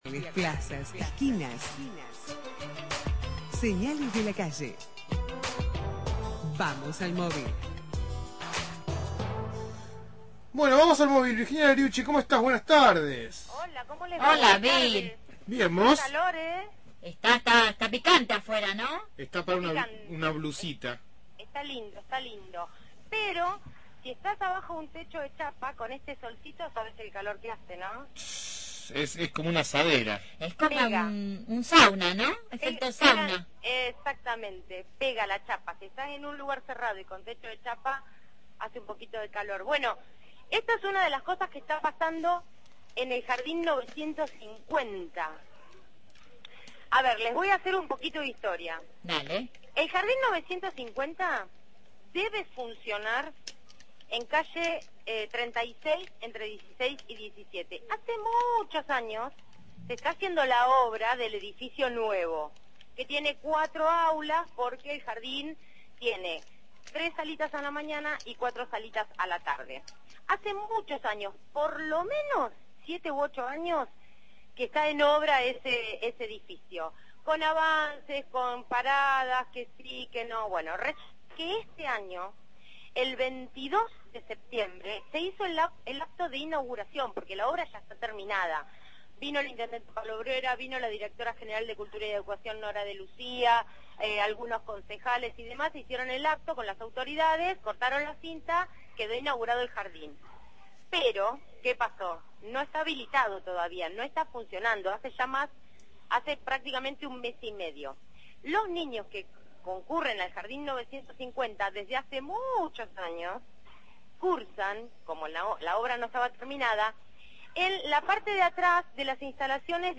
MÓVIL/ Edificio jardín 950 – Radio Universidad